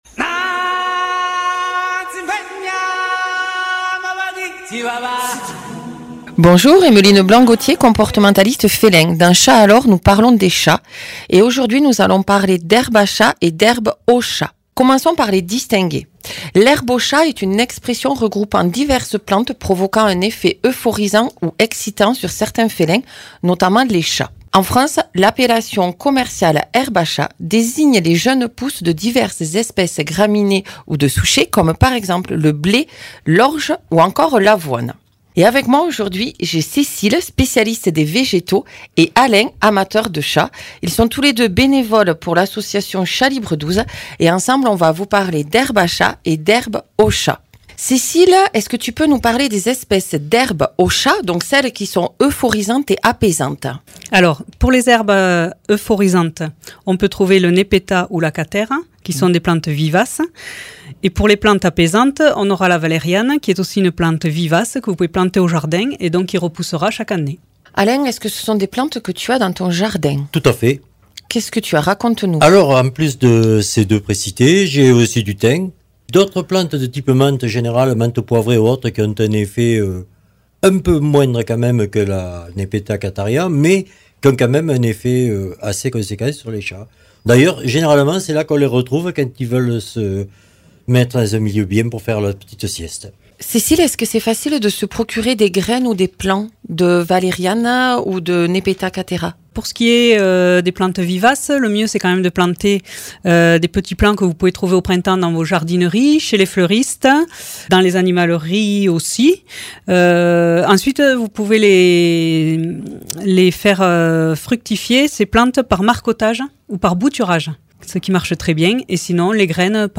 spécialiste des végétaux